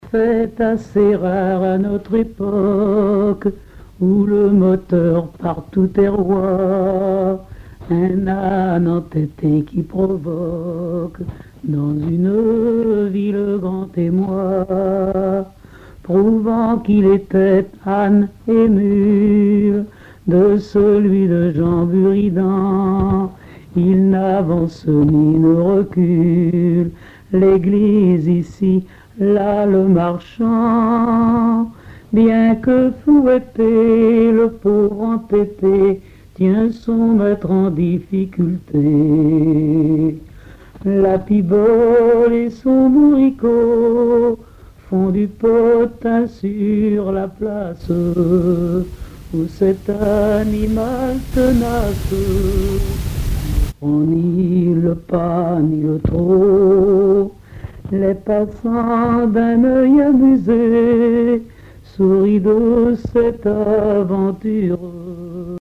Récits et chansons en patois
Pièce musicale inédite